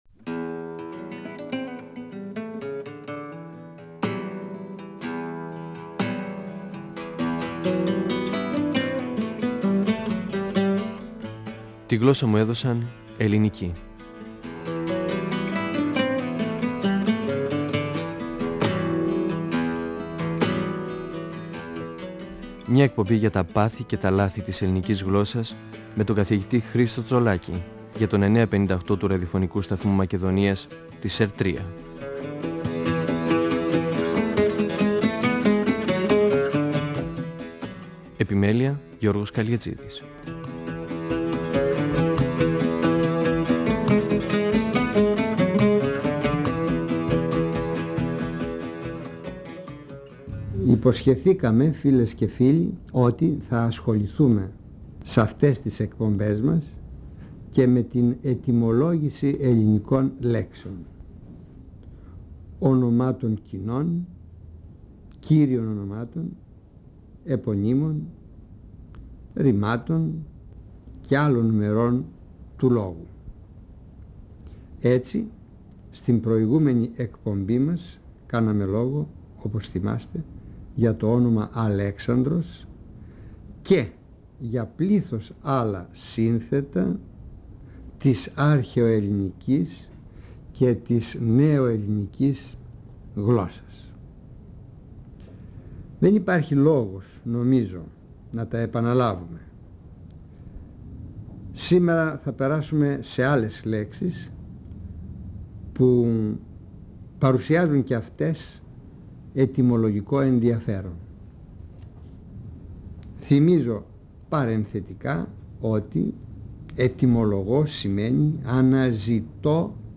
Ο γλωσσολόγος Χρίστος Τσολάκης (1935-2012) μιλά για την ετυμολογική ανάλυση των ονομάτων: Γεωργία, Γεώργιος, Βασιλεία, Βασίλειος.
Νησίδες & 9.58fm, 1999 (πρώτος, δεύτερος, τρίτος τόμος), 2006 (τέταρτος τόμος, πέμπτος τόμος). 958FM Αρχειο Φωνες Τη γλωσσα μου εδωσαν ελληνικη "Φωνές" από το Ραδιοφωνικό Αρχείο Εκπομπές ΕΡΤ3